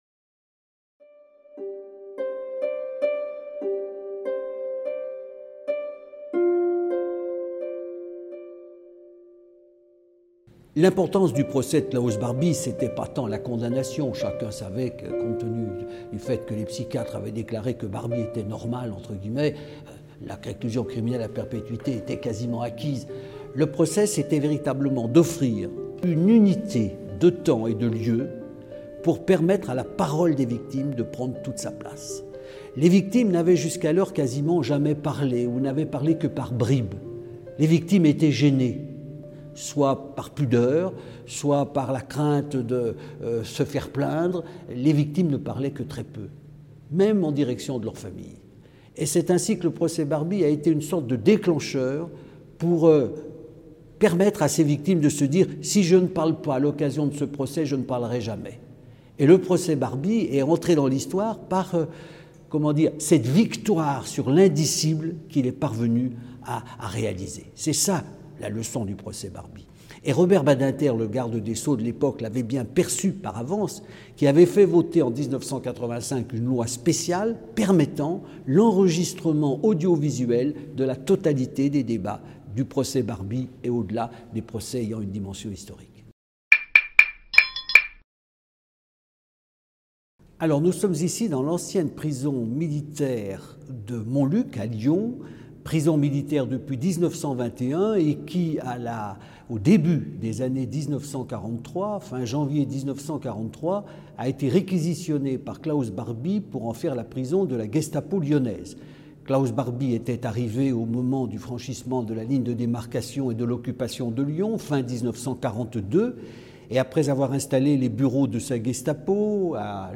Nous remercions le Mémorial national de la prison de Montluc (Lyon) pour son accueil. Cette prison, sur les glacis du fort de Montluc, a servi de lieu de rétention au régime de Vichy de 1940 à 1943, puis à la Gestapo lyonnaise (dirigée par K. Barbie) de février 1943 à août 1944.